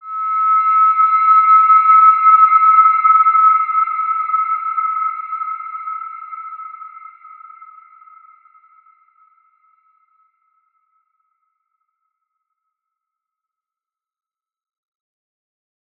Wide-Dimension-E5-p.wav